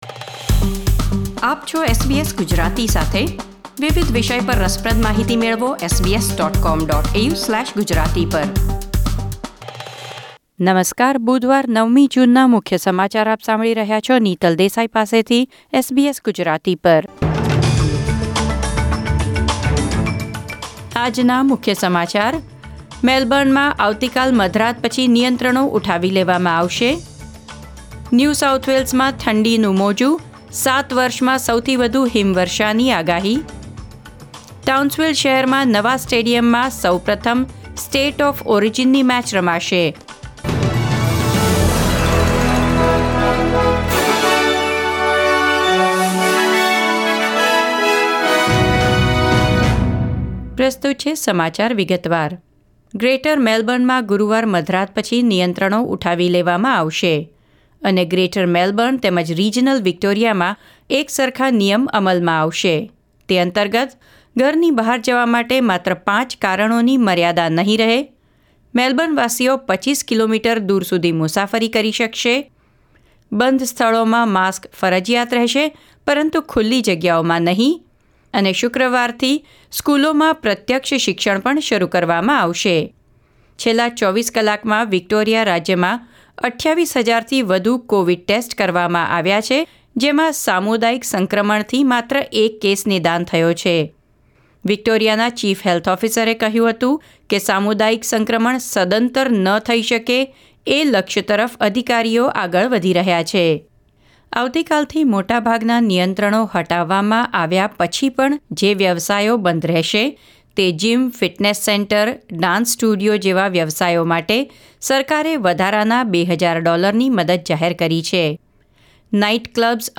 SBS Gujarati News Bulletin 9 June 2021